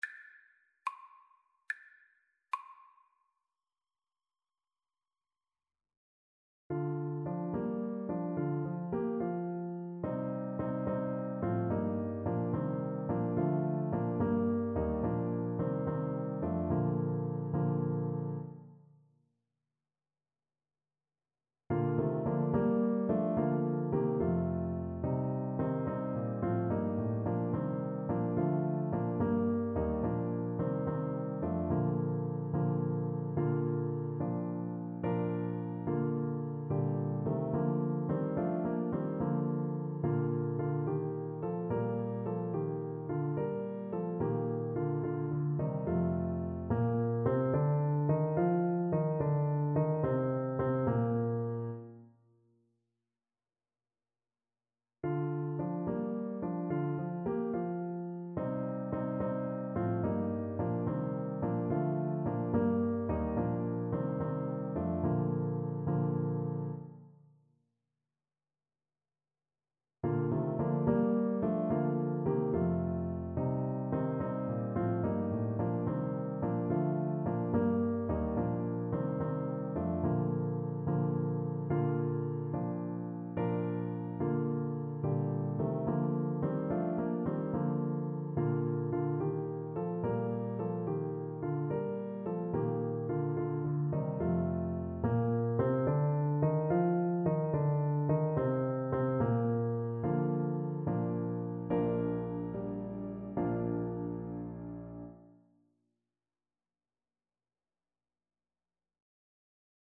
Alto Saxophone
6/8 (View more 6/8 Music)
Maestoso . = c. 72